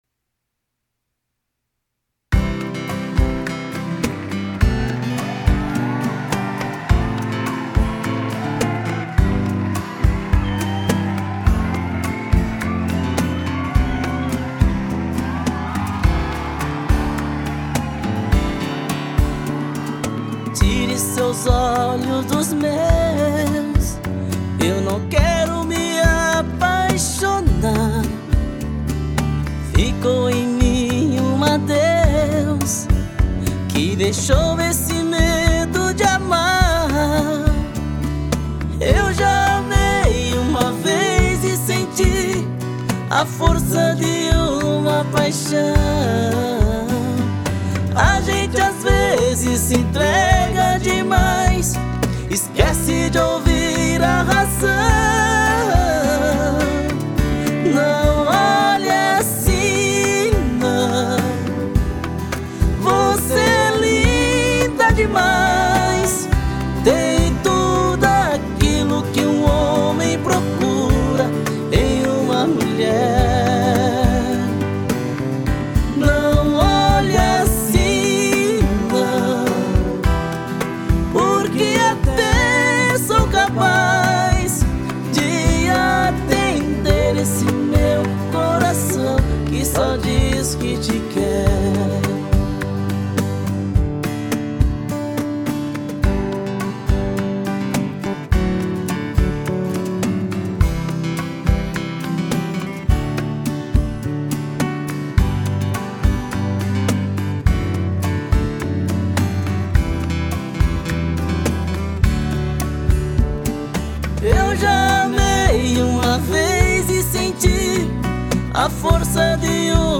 EstiloSertanejo